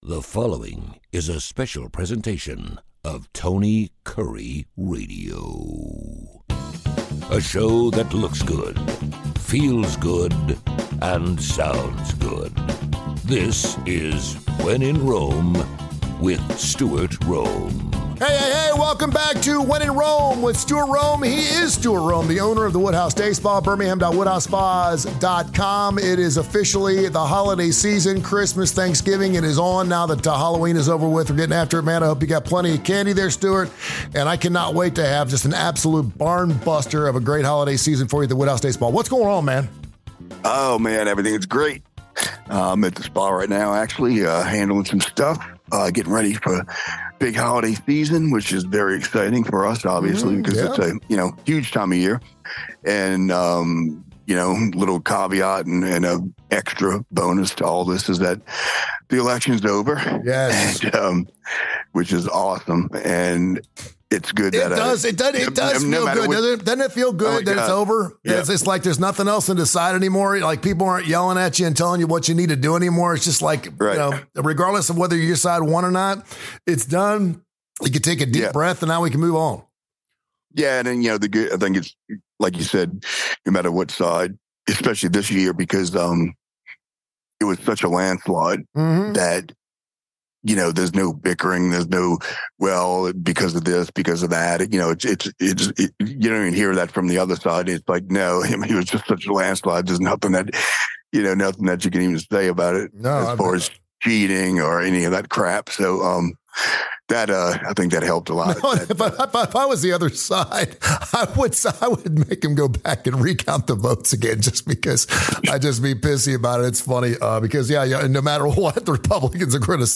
Now that the election is over, it's time to chill! Check out these acoustic rockers PLUS the unbelievable Black Friday deals at Woodhouse Day Spa.